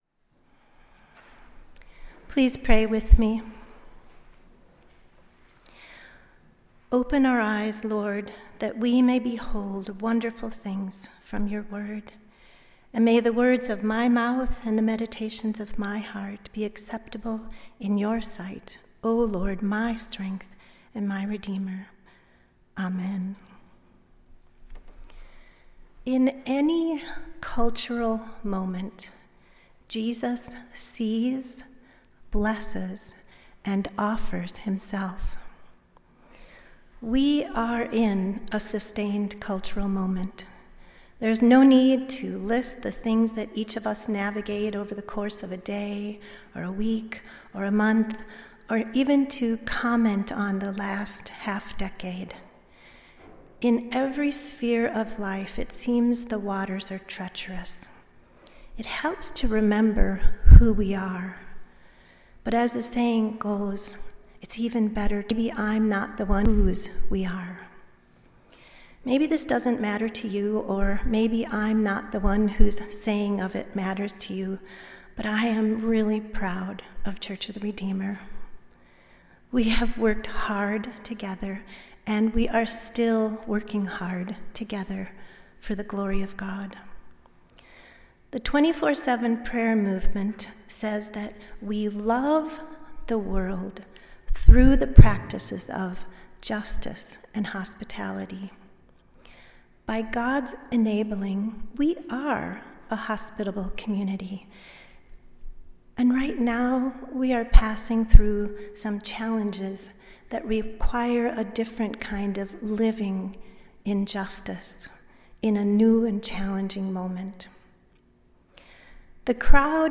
Sunday Worship–February 8, 2026
Sermons